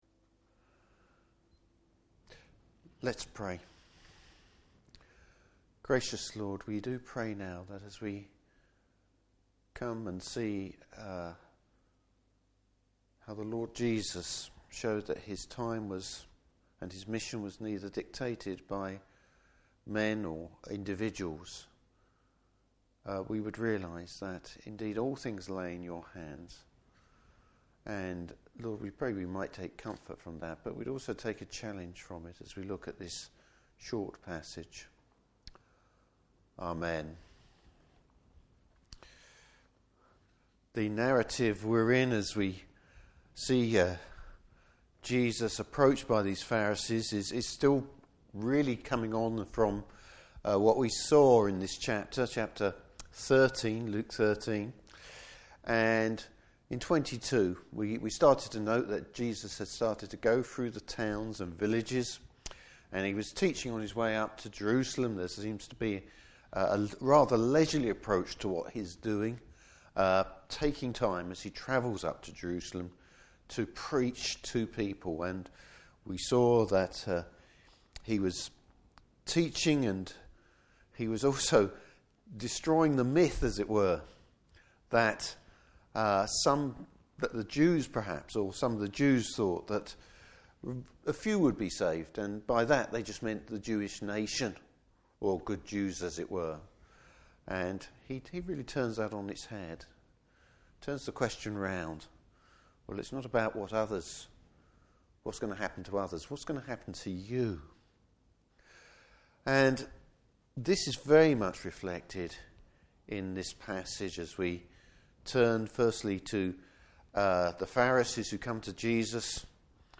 Service Type: Morning Service Bible Text: Luke 13:31-35.